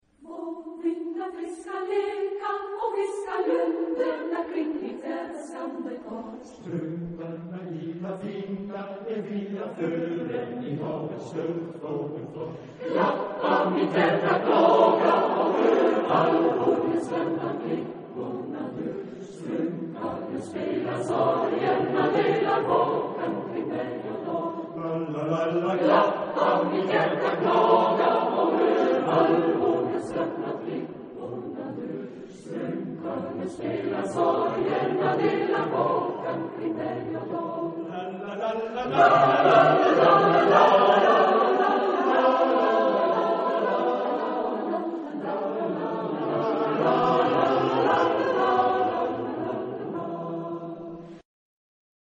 Genre-Style-Forme : Chanson ; Folklore ; Profane
Caractère de la pièce : romantique ; nostalgique ; lent
Type de choeur : SATB  (4 voix mixtes )
Tonalité : mi mineur